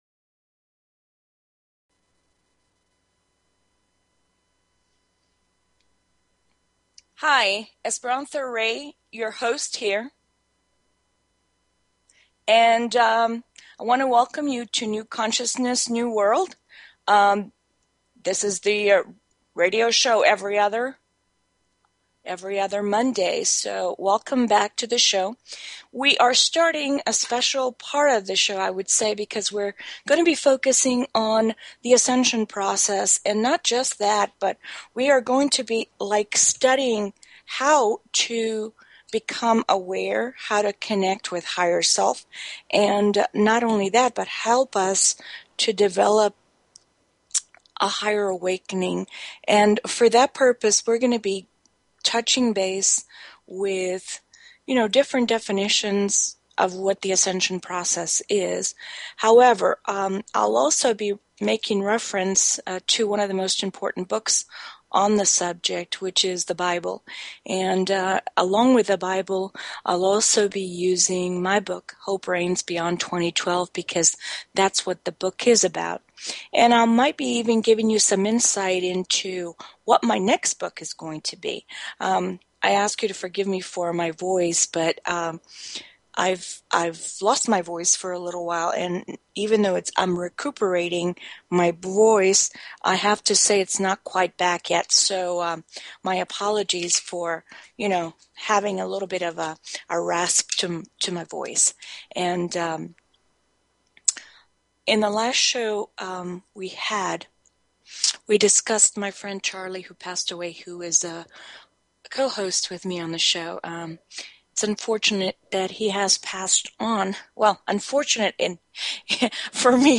Talk Show Episode, Audio Podcast, New_Consciousness_New_World and Courtesy of BBS Radio on , show guests , about , categorized as